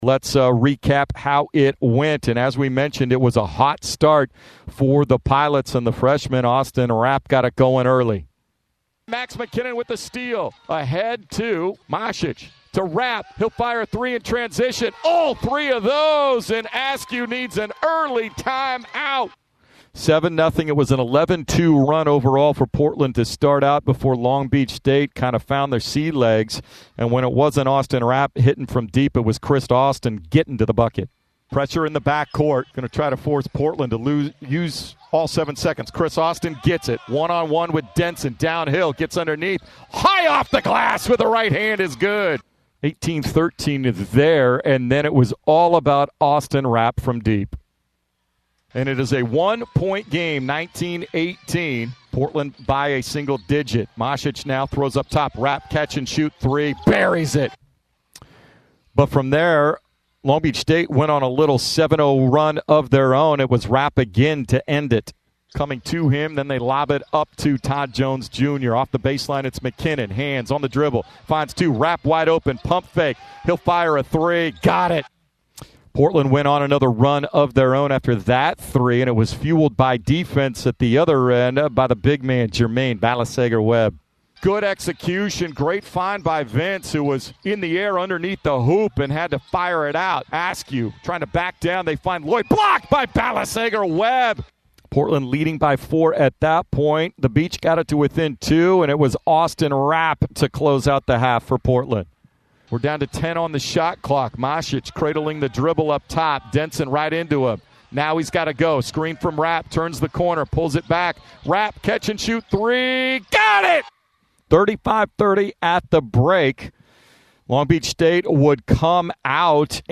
November 16, 2024 Radio highlights from Portland's 63-61 win at Long Beach State on Nov. 16, 2024.